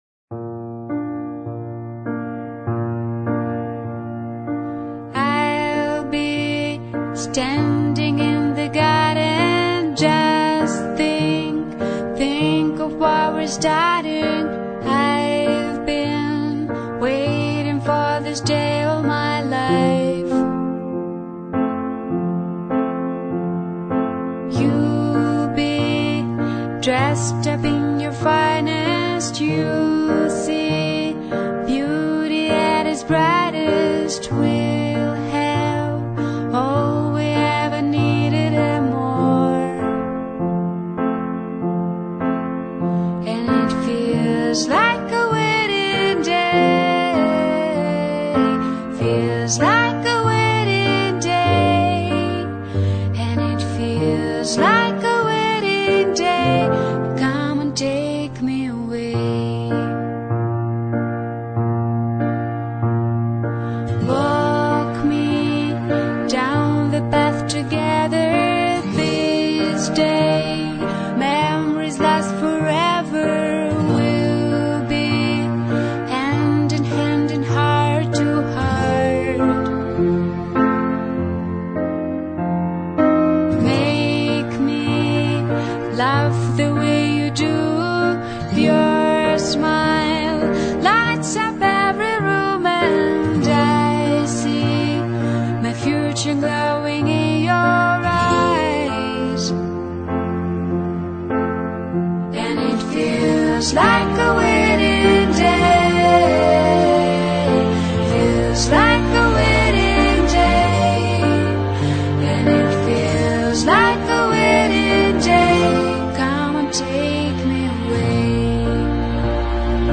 描述：这是一首美丽、真挚的婚礼歌曲，浪漫而又轻松、轻松、欢快而又衷心，多愁善感而又令人振奋。